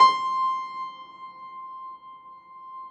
53g-pno18-C4.wav